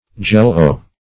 Jell-O \Jell"-O\, jello \jell"o\n. [a trademark.]